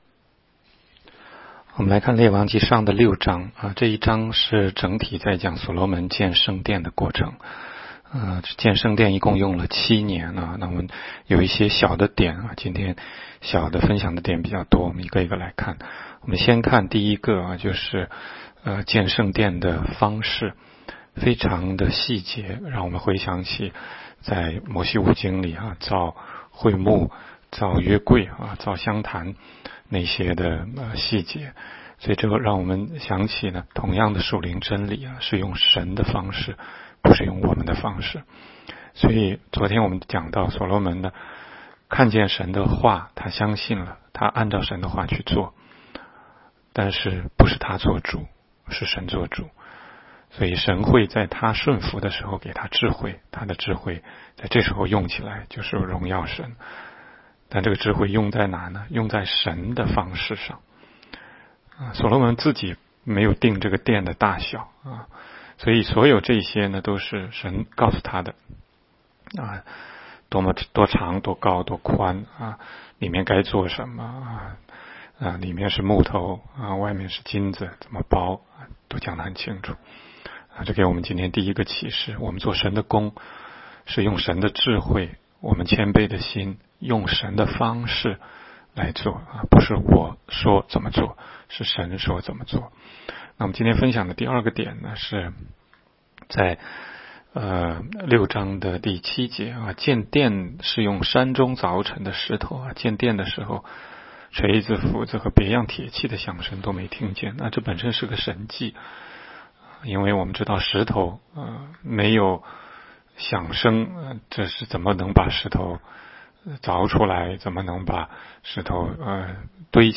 16街讲道录音 - 每日读经-《列王纪上》6章